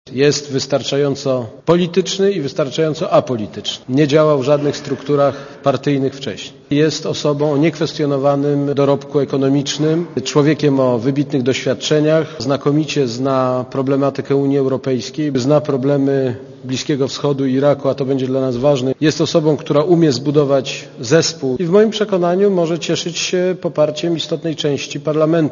Posłuchaj komentarza prezydenta